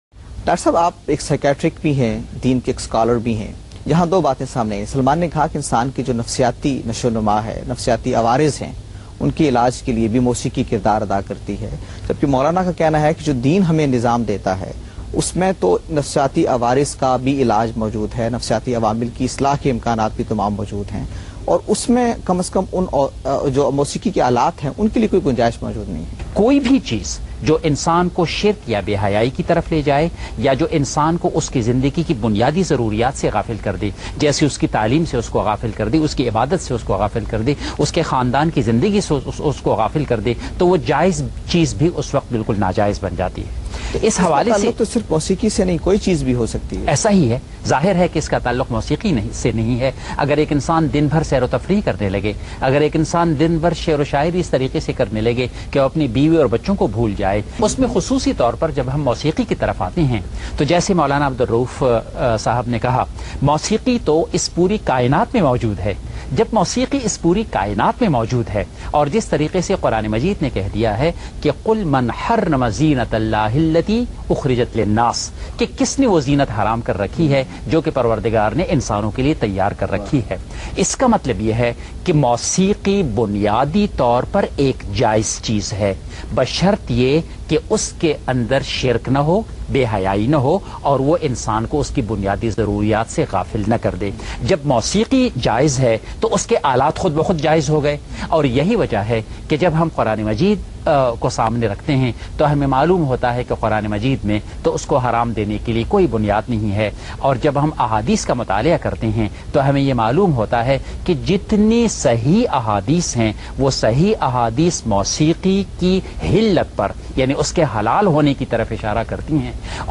Category: TV Programs / Geo Tv / Alif /